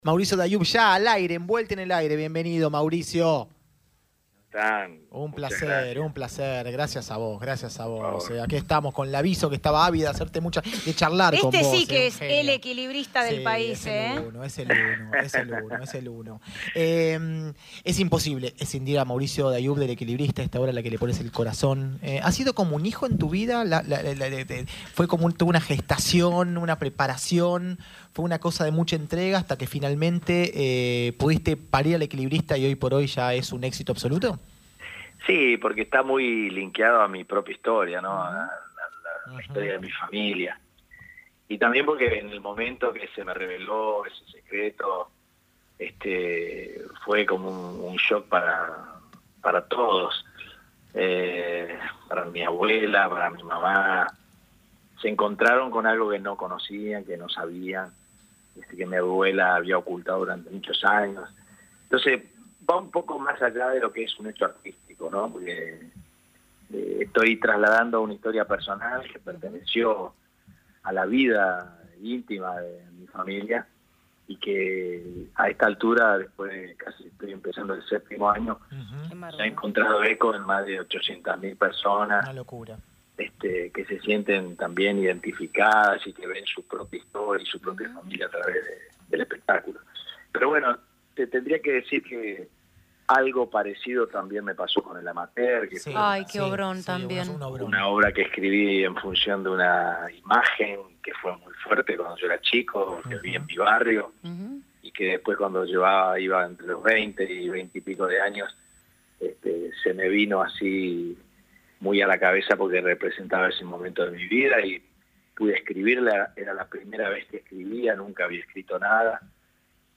dialogan con el gran actor Mauricio Dayub